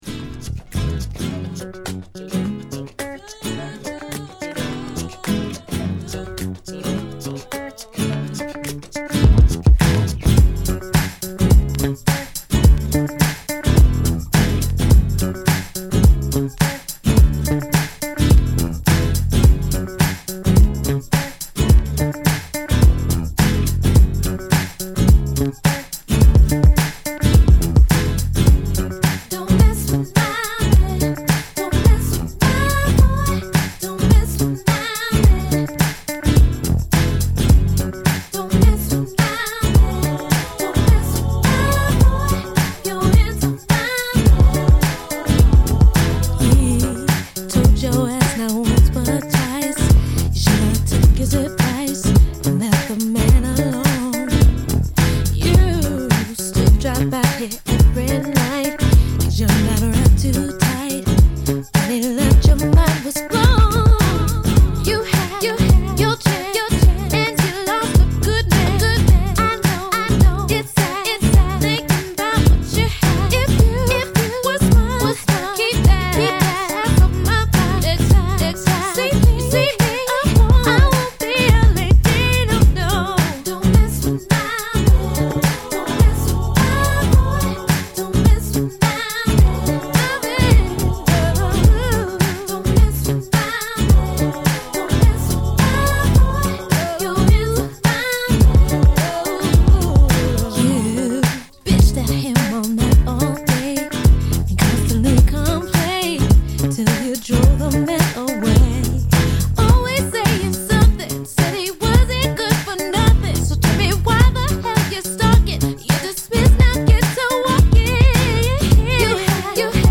dance/electronic
UK-garage